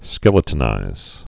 (skĕlĭ-tn-īz)